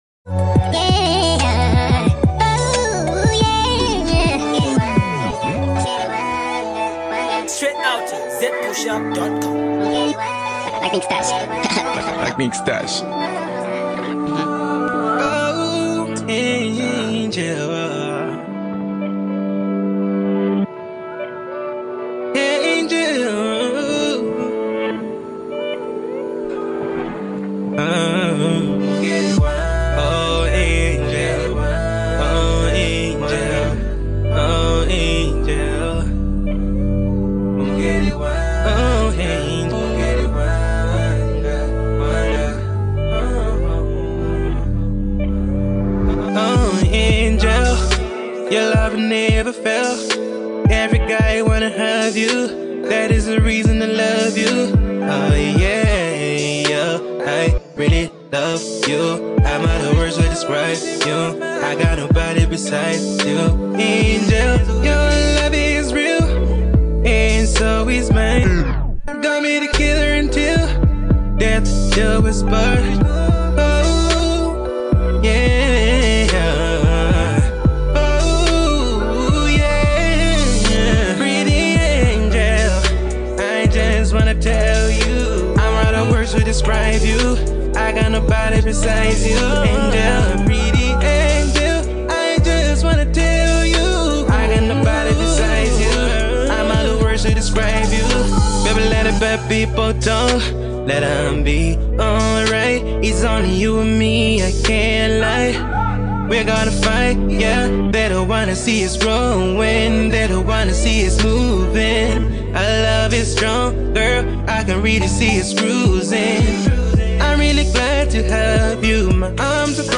slow heart touching joint